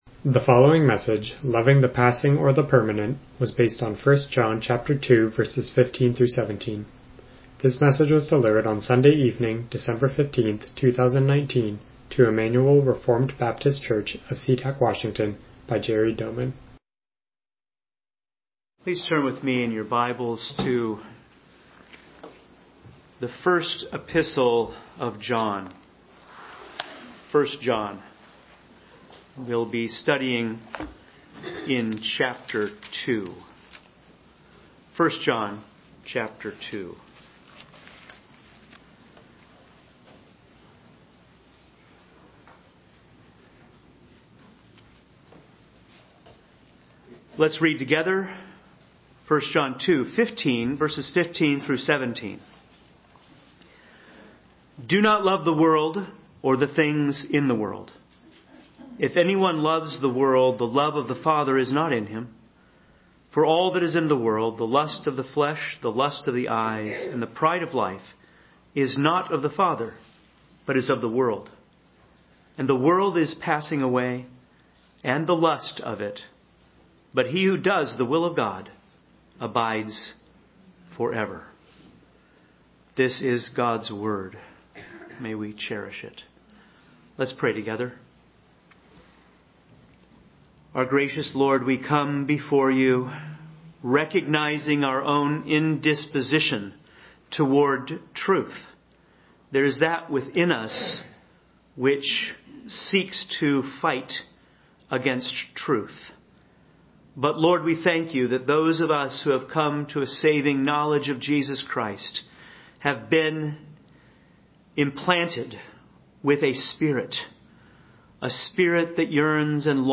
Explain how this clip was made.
1 John 2:15-17 Service Type: Evening Worship « God’s Great Rescue Walking on Water